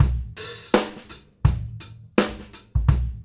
GROOVE 140DL.wav